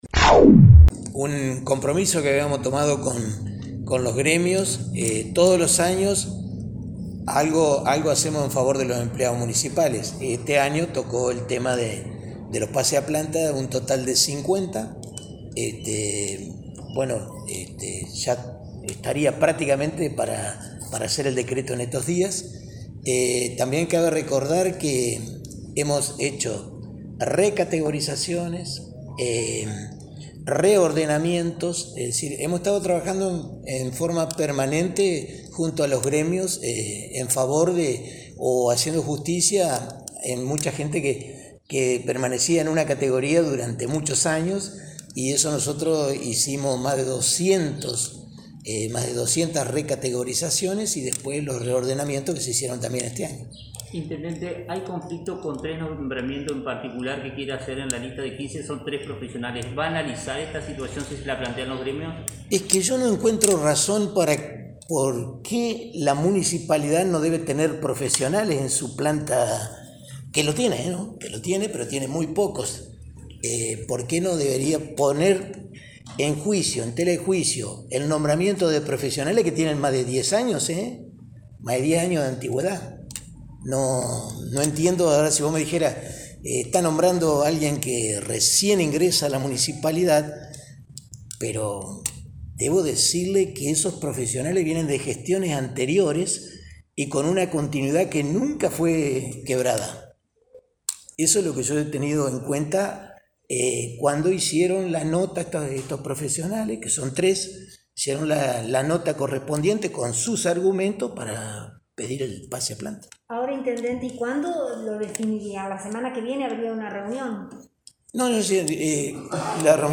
“Todos los años hacemos algo en favor de los empleados municipales, este año tocó el tema de los pases a Planta”, comenzó declarando el intendente de Victoria a los medios en una conferencia de prensa donde se le preguntó sobre los pases a Planta Permanente.
intendente-pases-a-planta-web.mp3